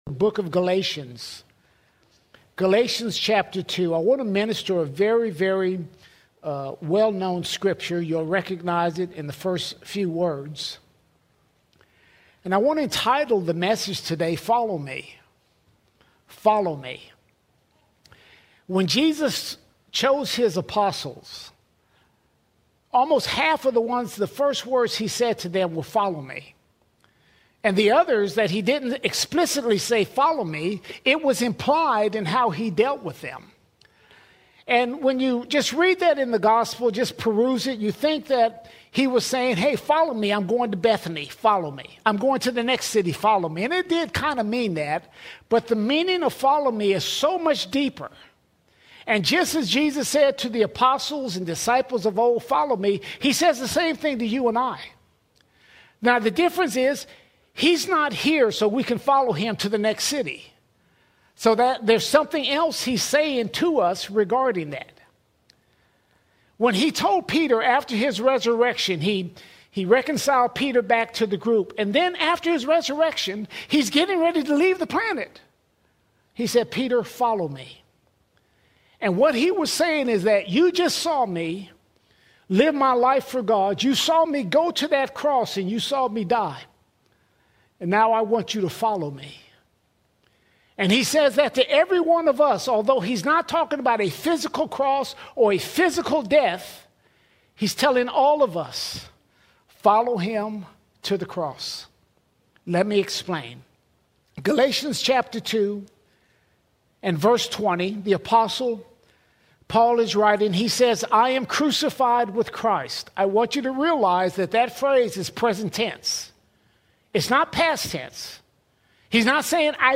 2 November 2025 Series: Sunday Sermons All Sermons Follow Me Follow Me Galatians 2:20 calls us to follow Christ fully - crucifying self, surrendering will, and silencing self-preservation.